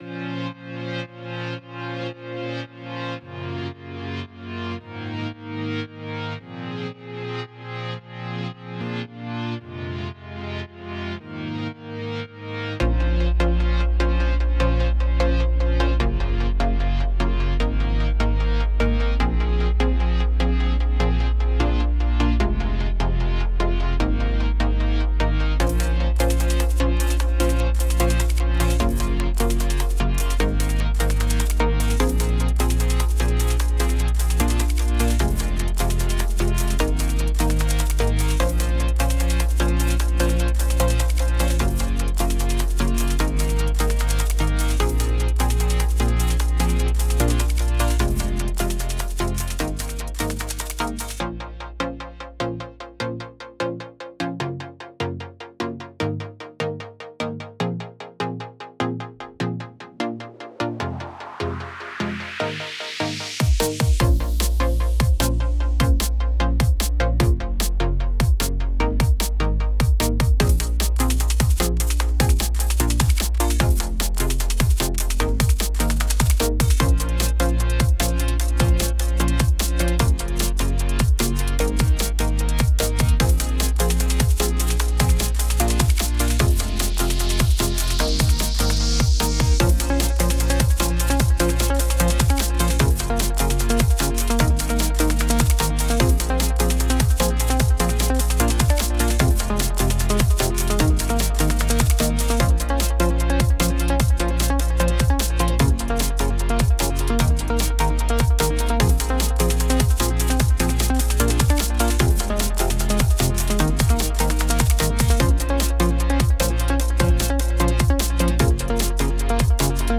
dnb - snoozy music
couple of drums, some bass